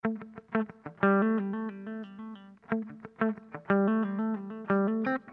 Sons et loops gratuits de guitares rythmiques 100bpm
Guitare rythmique 68